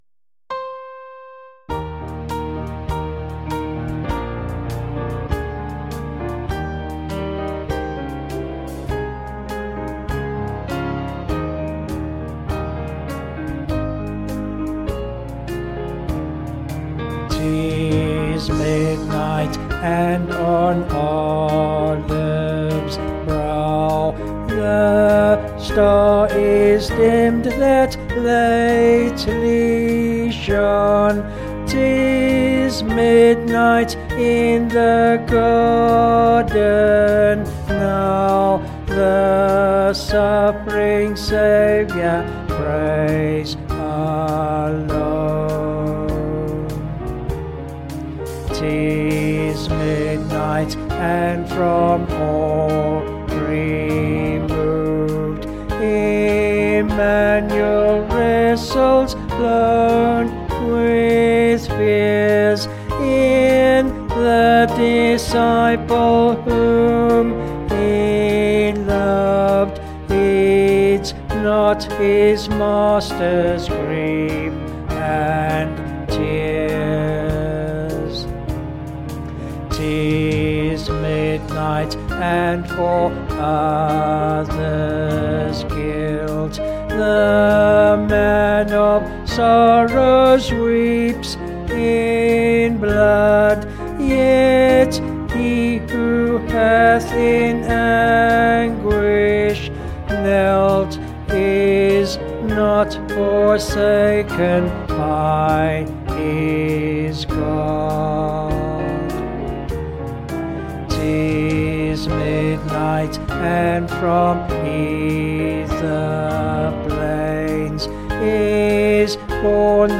4/Ab
Vocals and Band   264.5kb Sung Lyrics